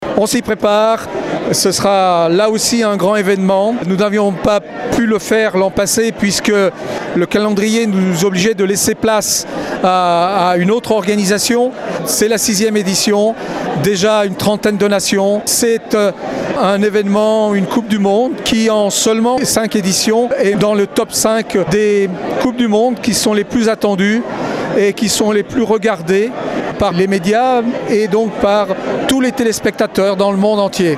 La réputation de cette coupe du monde n’est désormais plus à faire comme le confirme, André Perrillat-Amédé le président du comité d’organisation, qui est aussi le maire du Grand Bornand :